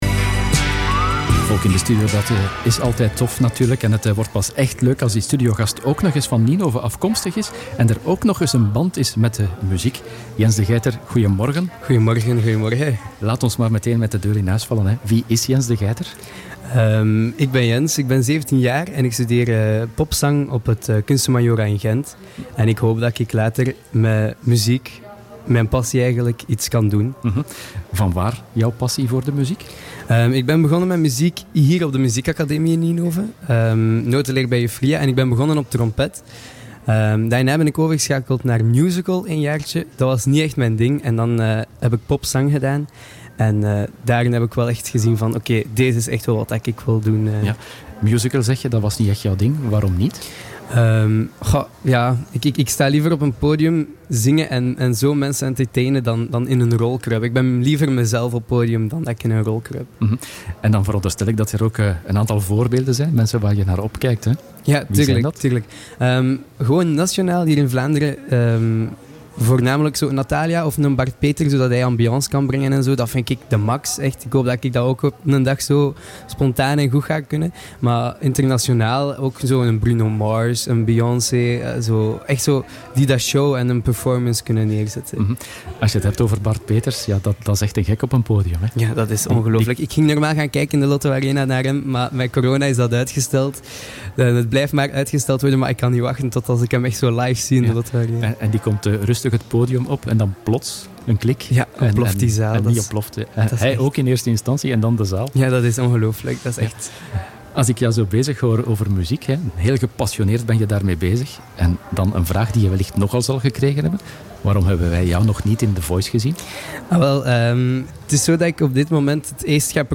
Radio Ninove
Hij had het onder meer over zijn twee singles die hij uitbracht. Beluister het volledige interview hieronder.